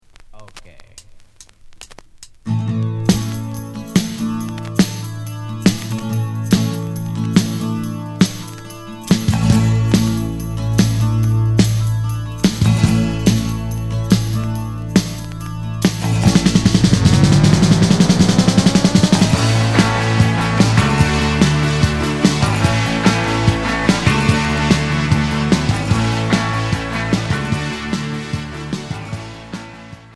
Rock blues Premier 45t retour à l'accueil